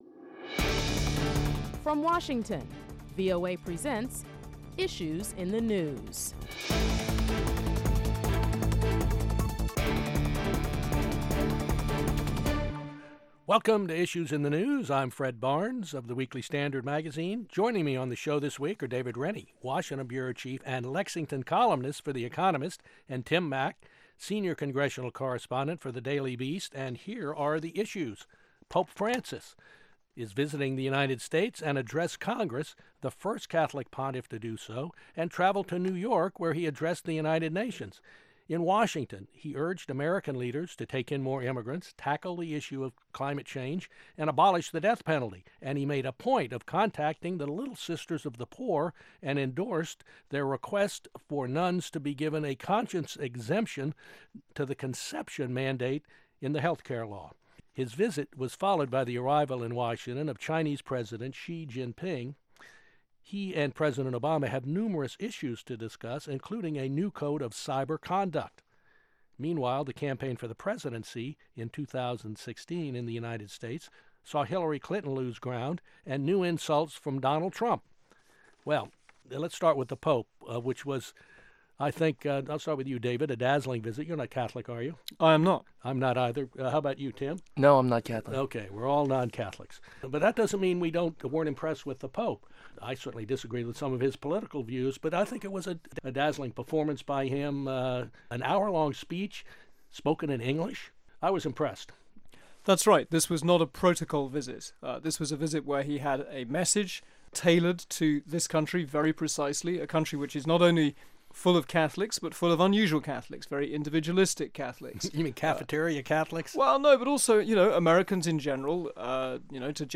Moderator Fred Barnes, Executive Editor of The Weekly Standard